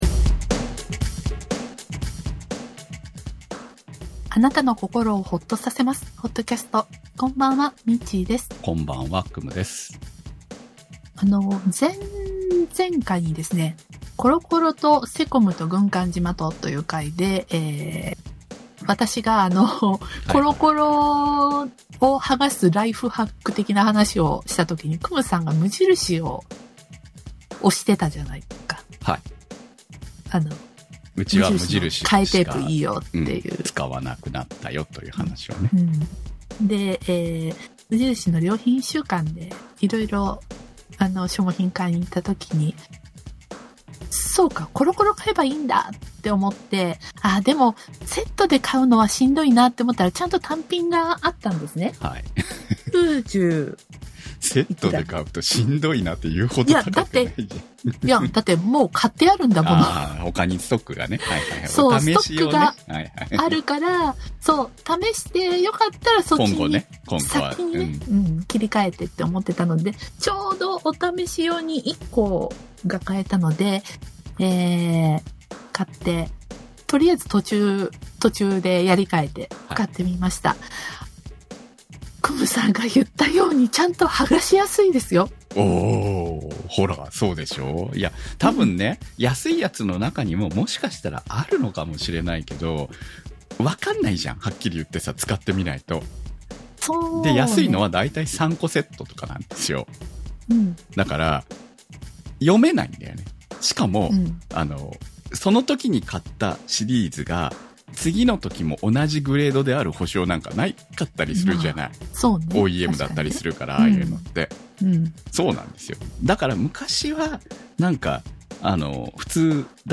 非常にお聞き苦しい状況です。